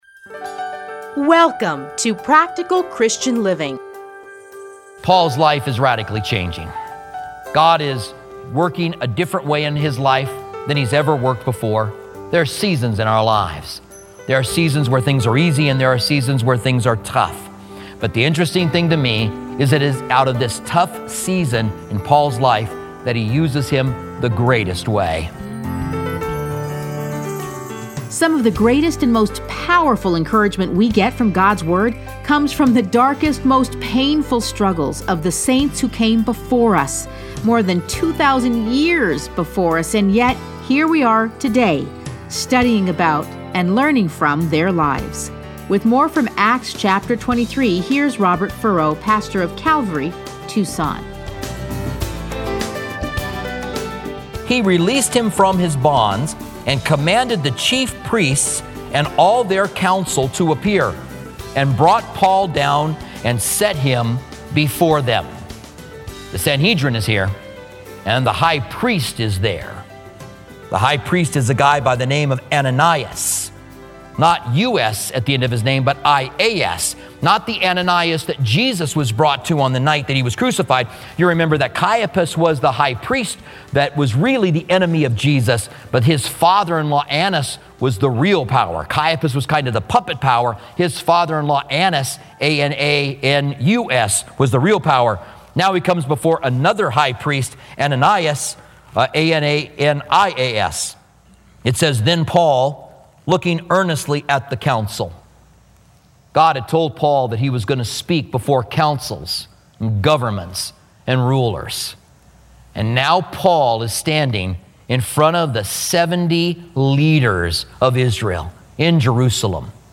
Listen to a teaching from Acts 23.